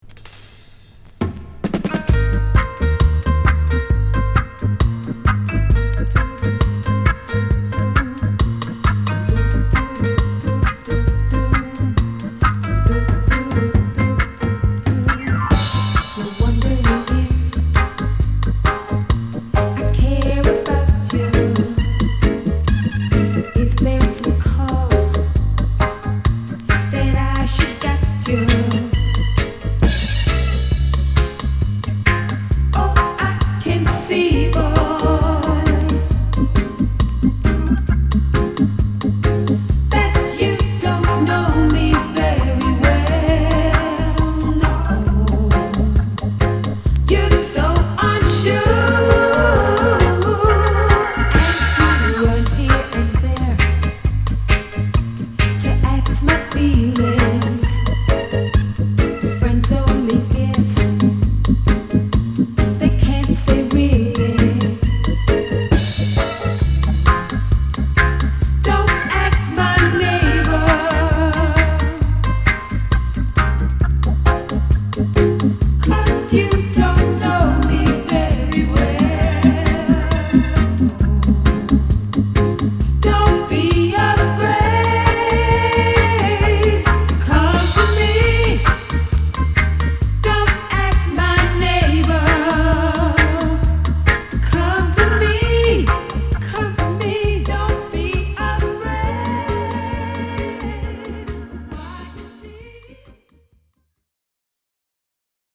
ホーム 45's REGGAE S